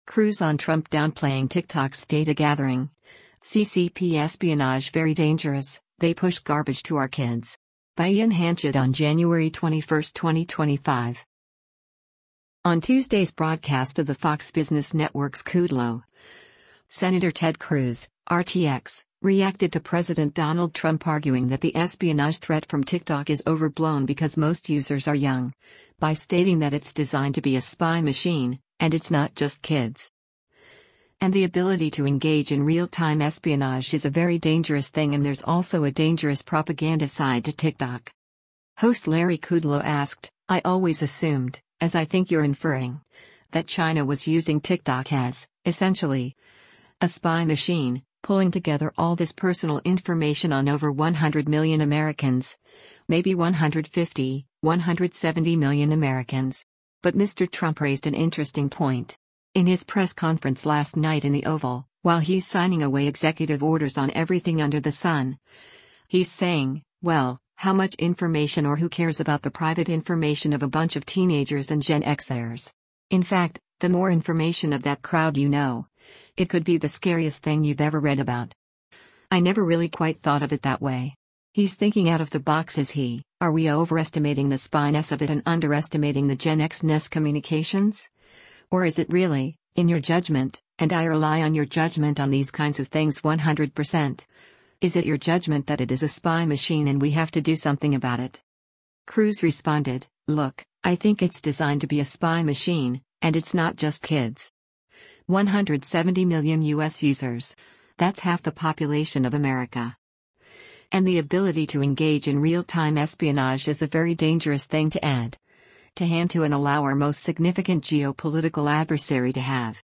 On Tuesday’s broadcast of the Fox Business Network’s “Kudlow,” Sen. Ted Cruz (R-TX) reacted to President Donald Trump arguing that the espionage threat from TikTok is overblown because most users are young, by stating that “it’s designed to be a spy machine, and it’s not just kids.”